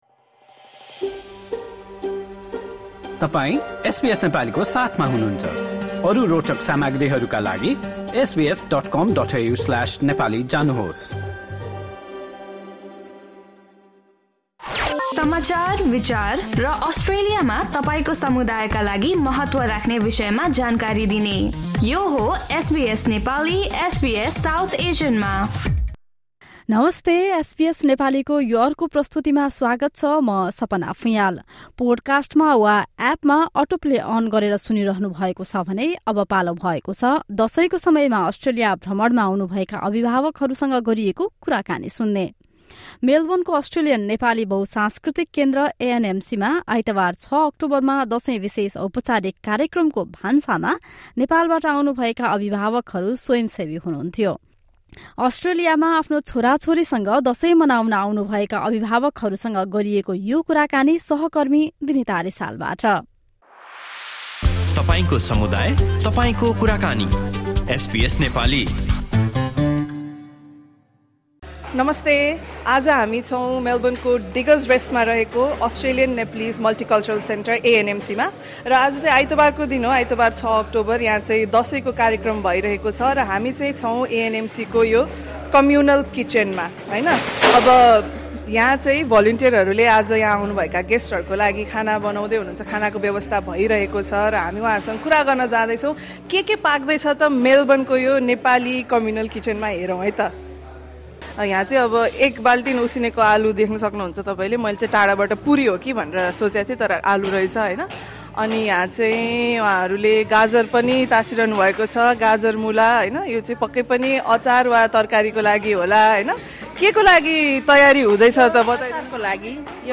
Nepali parents visiting Australia spoke to SBS Nepali during a Dashain program at the Australian Nepali Multicultural Centre (ANMC). Many of them who volunteered at the centre's communal kitchen on Sunday, 6 October say while they are glad to celebrate festivities with their children in Australia, it is sad to see many Nepalis leaving their country.